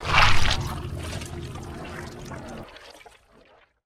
TentacleAttack1.ogg